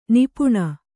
♪ nipuṇa